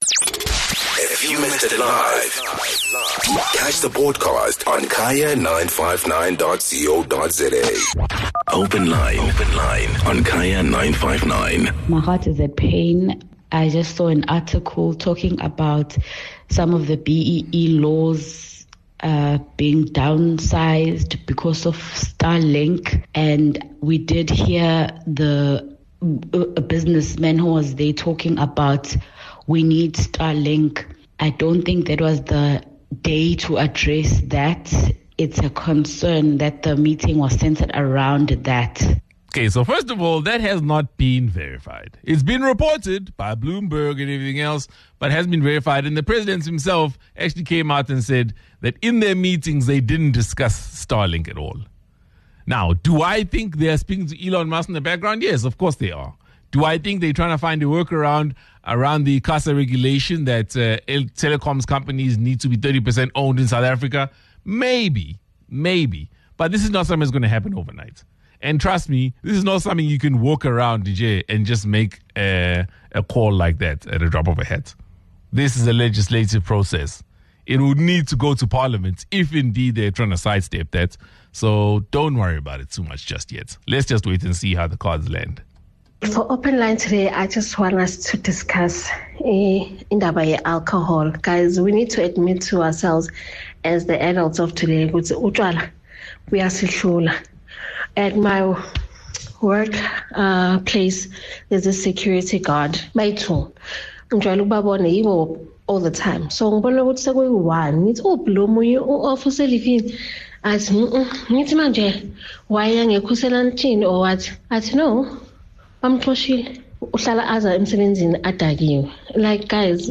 During the Open Line Hour, listeners are invited to discuss any topic they’d like to hear the Siz the World team’s views on. Today, they covered a range of issues, including toll payments, the role of the motor ombudsman, traffic congestion, and the negative impact of alcohol on job performance.